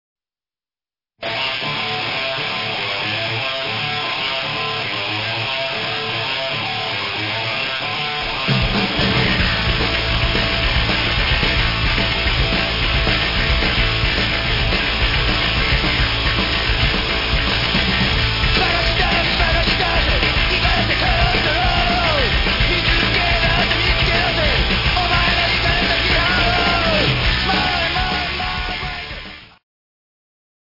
ドライブ感あふれるパンクロックの嵐！